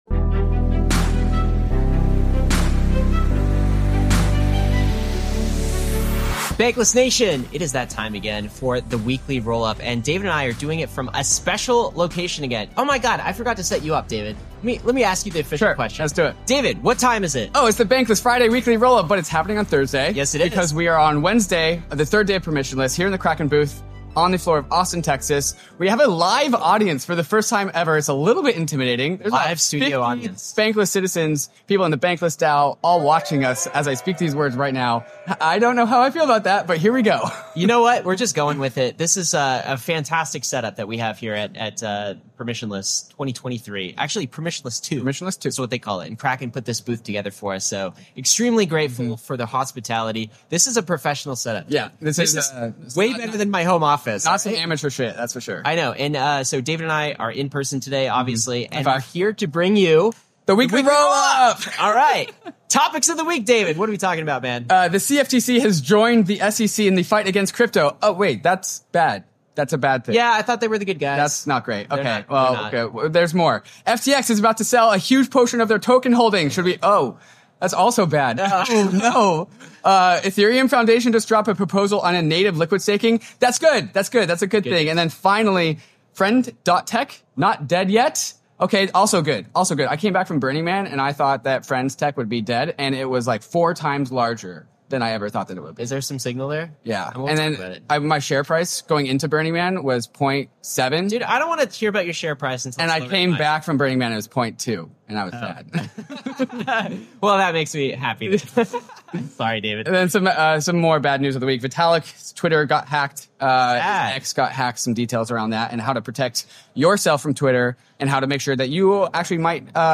ROLLUP: CFTC's Attack On Crypto | FTX Offloading Billions | Live From Permissionless!